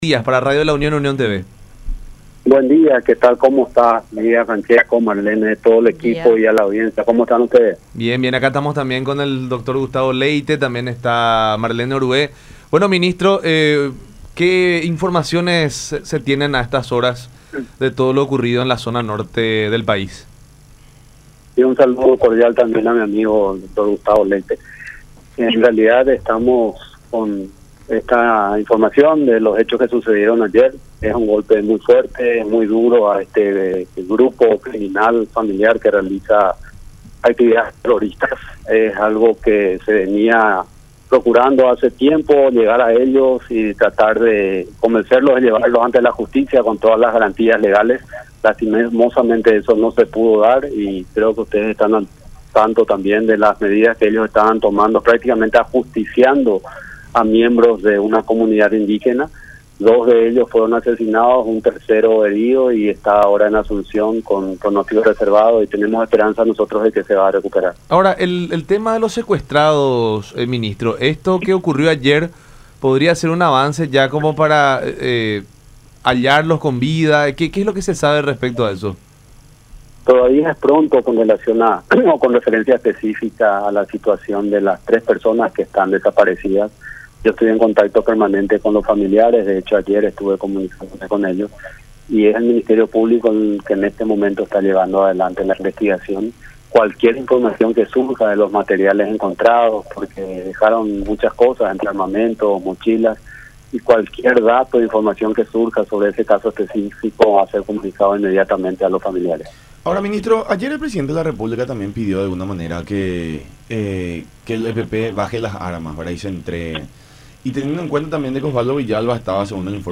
Ellos estaban prácticamente ajusticiando a miembros de una comunidad indígena”, dijo González en charla con La Unión Hace La Fuerza por Unión TV y radio La Unión.